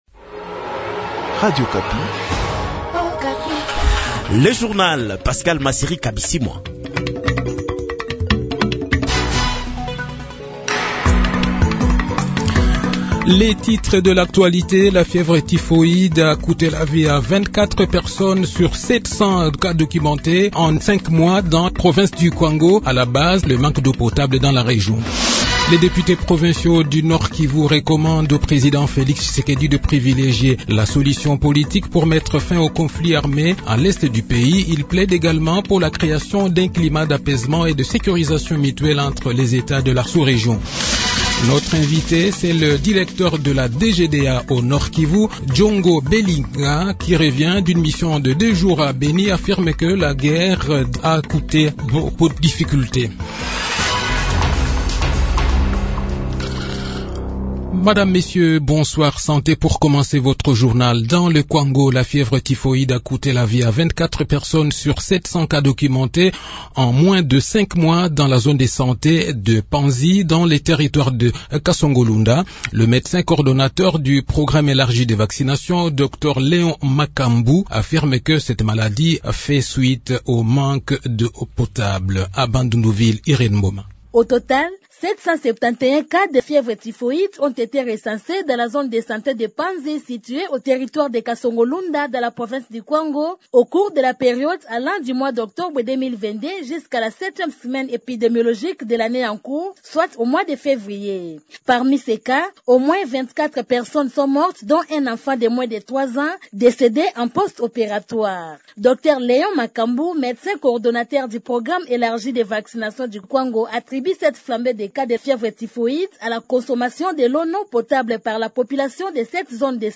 Le journal de 18 h, 1 mars 2023